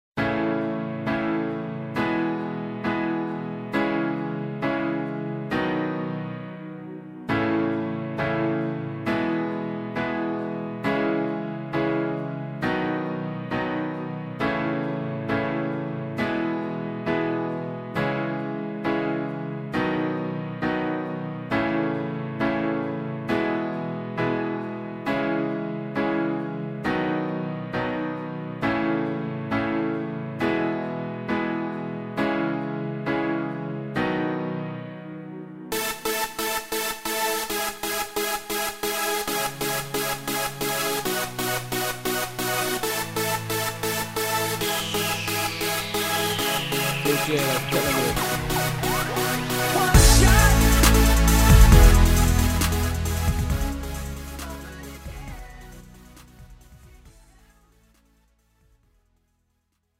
고음질 반주